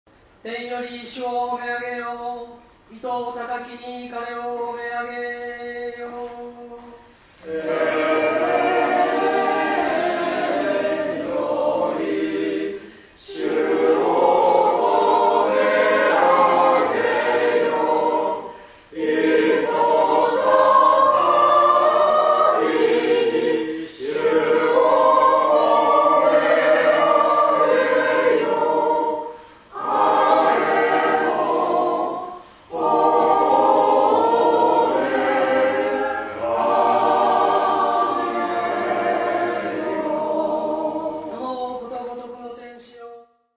新しい聖堂は天井が高く、堂内に木を多用してあるため、柔らかく響きます。
We have been advocating congregational singing, now almost all the attendants participate singing.
○聖体礼儀から　　Live recording at Divine Liturgy on Aug. 21　NEW
Communion Hymn　modern Greek
神品領聖の時間を満たすために１４８聖詠を一句ごと区切って誦経者が唱え（歌い）、聖歌隊が同じメロディを繰り返す。